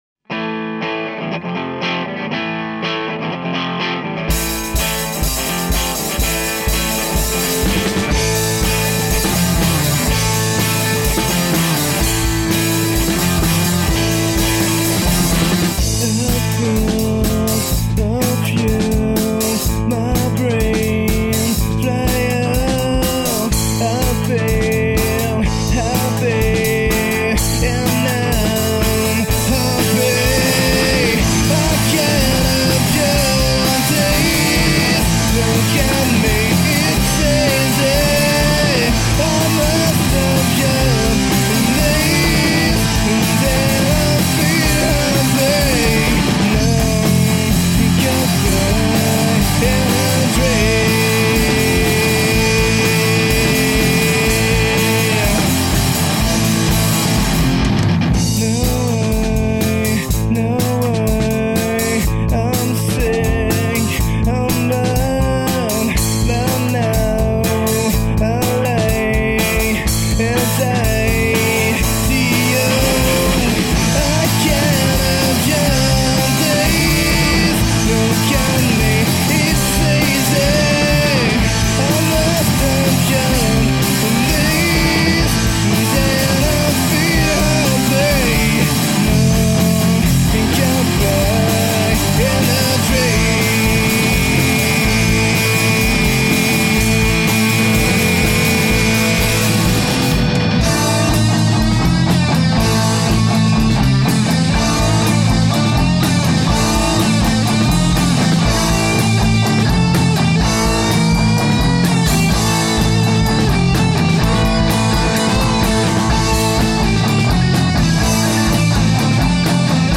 Grunge